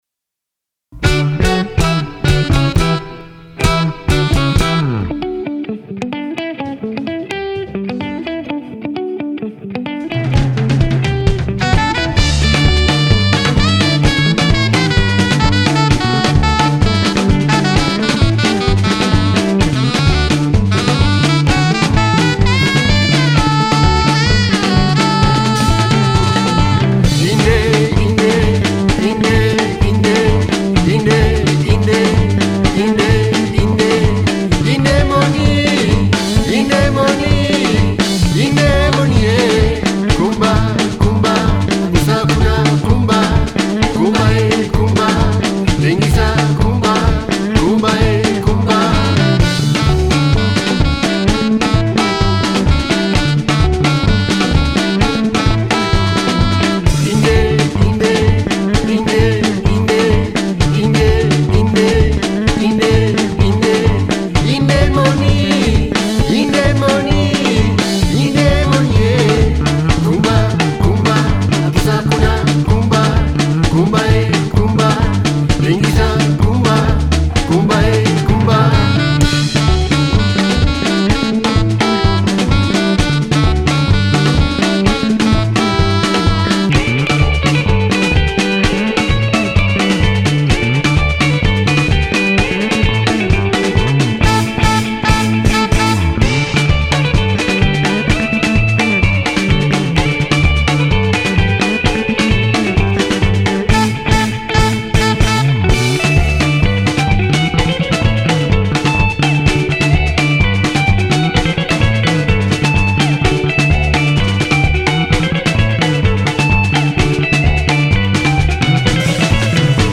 Soukous Nederland